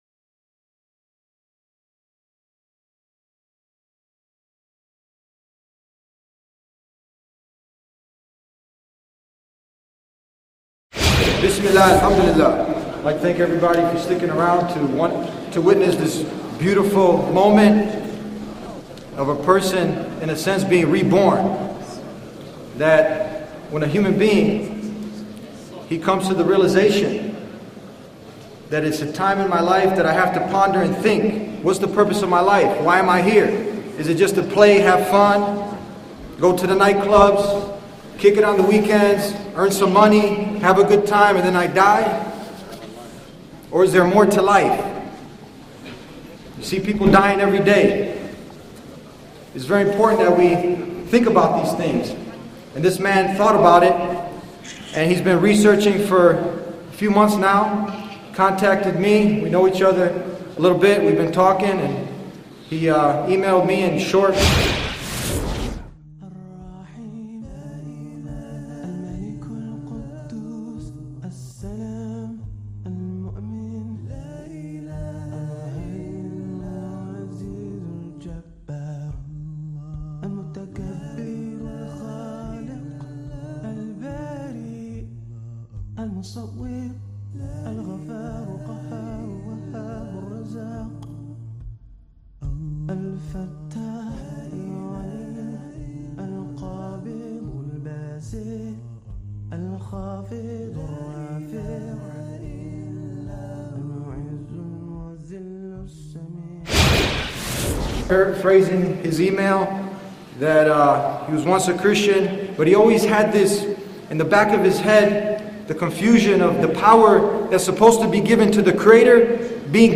In this moving episode, we witness a professional American architect take his shahada (declaration of faith) and embrace Islam after months of sincere research and reflection. His journey from having no religious guidance — and even dabbling in witchcraft and superstition — to finding the pure monotheism of Islam is a powerful reminder that Allah guides those who sincerely seek the truth, no matter where they start.